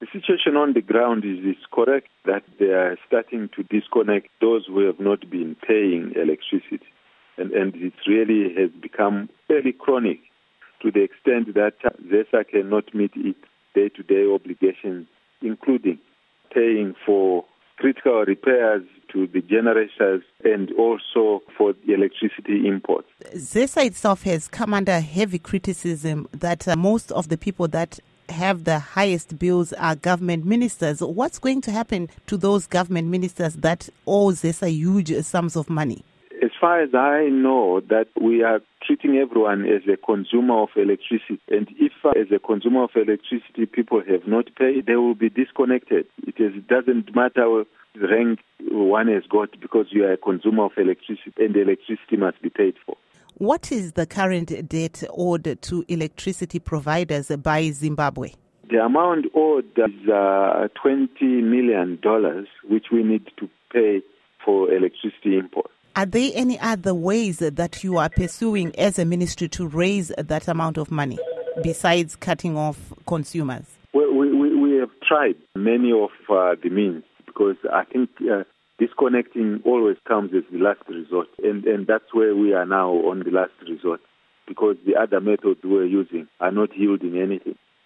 Interview With Elton Mangoma